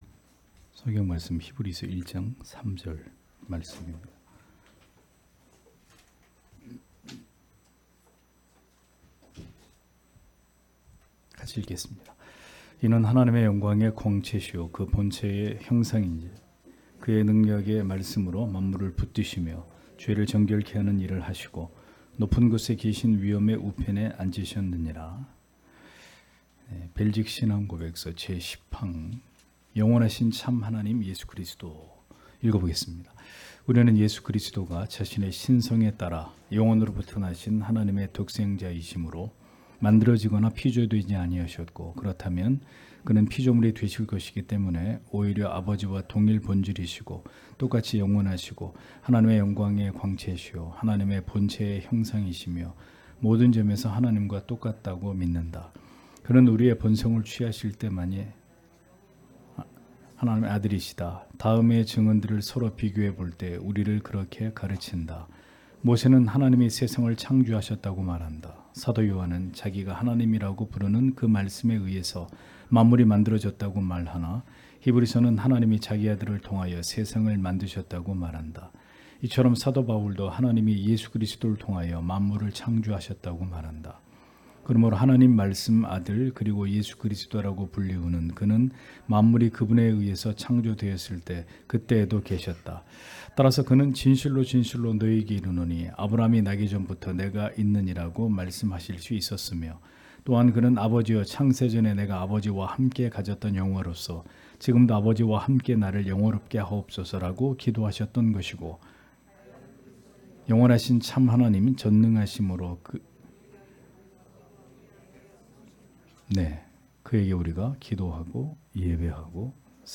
주일오후예배 - [벨직 신앙고백서 해설 10] 제10항 영원하신 참 하나님 예수 그리스도 (히 1:3)